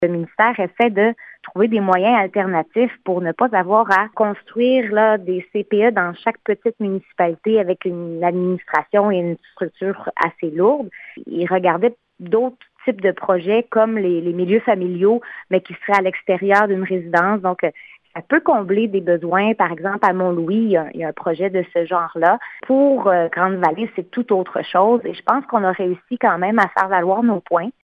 La députée de Gaspé explique que le ministère de la Famille voudrait opter pour un projet pilote de garderie en milieu familial à l’extérieure des résidences :